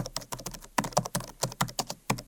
keypad.ogg